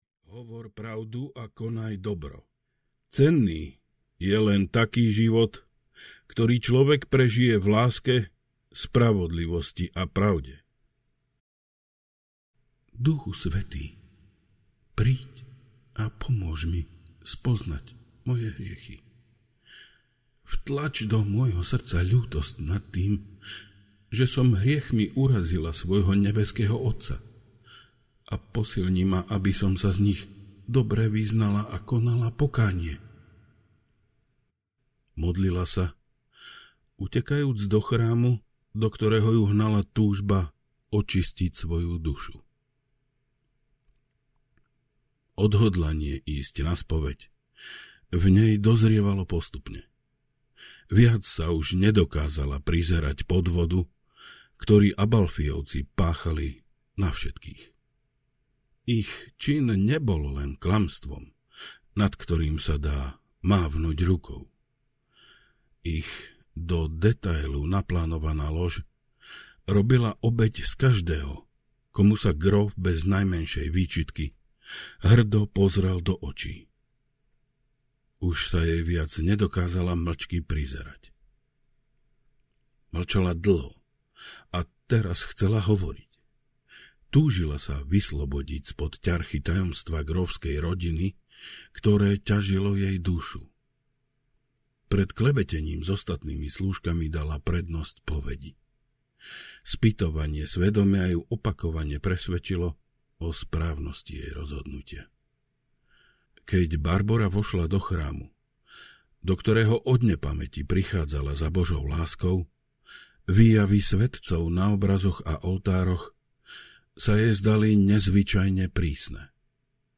Pliaga audiokniha
Ukázka z knihy